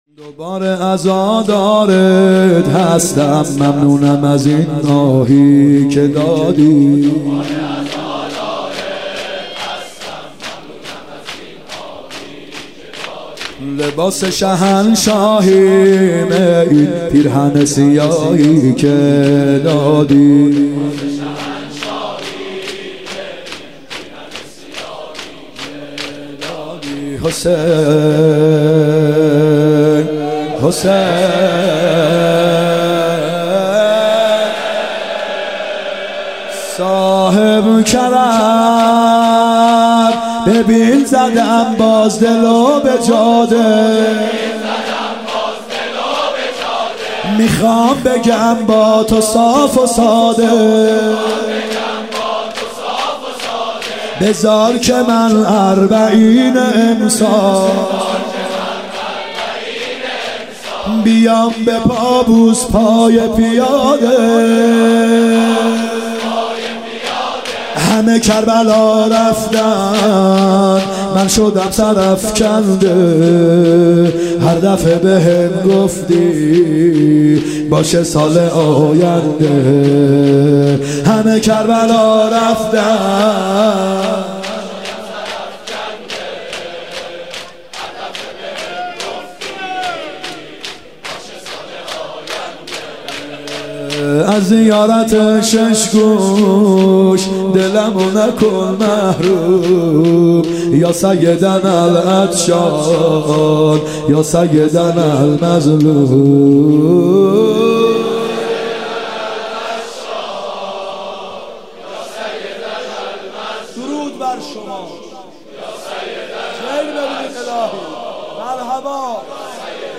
محرم 94 شب هفنم شور سرود پایانی ( دوباره عزادارت هستم ممنونم از این اهی که دادی)
محرم 94(هیات یا مهدی عج)